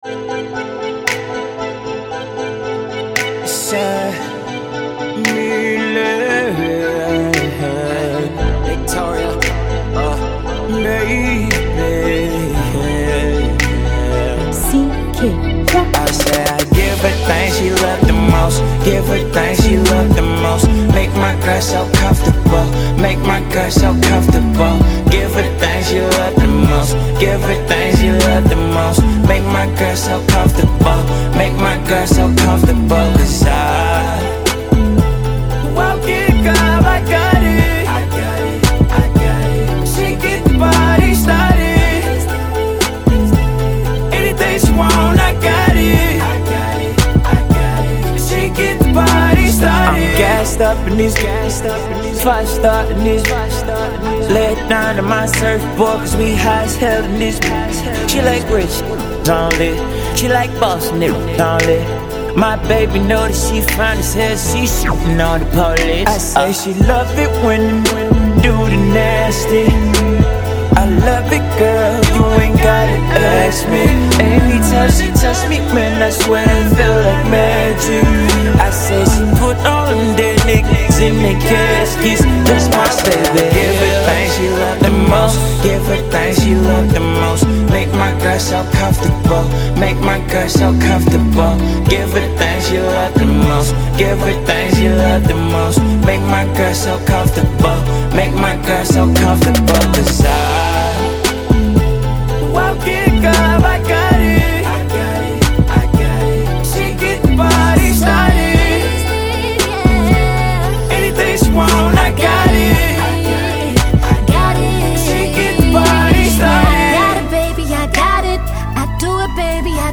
R and B